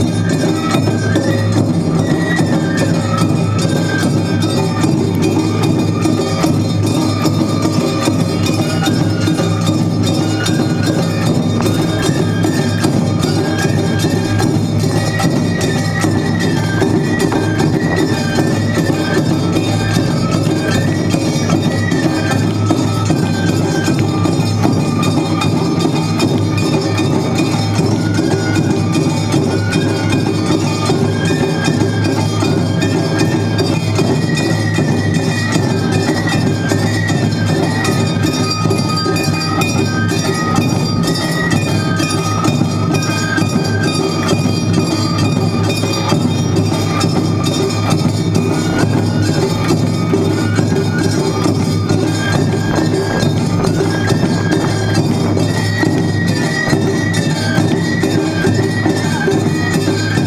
−　阿波踊り　−
街中ではどこからともなく阿波踊りの音楽が